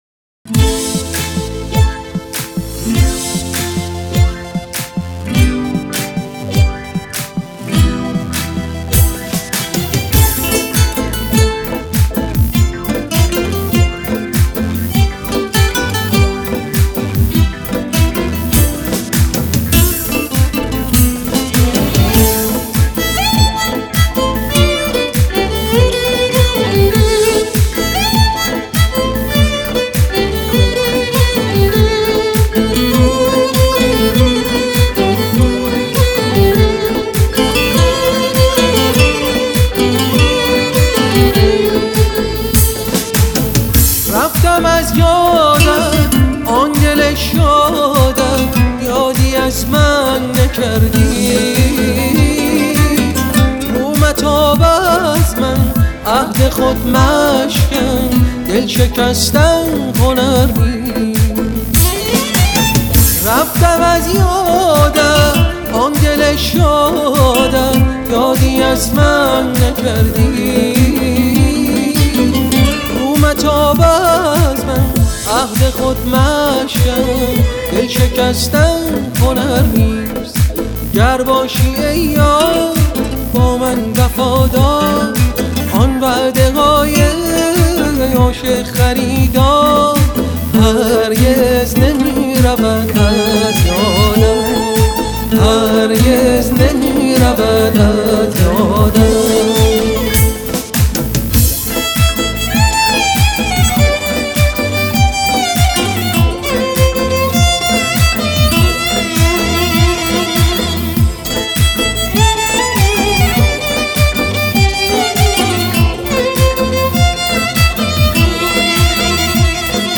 ویولن
گیتار
تک اهنگ ایرانی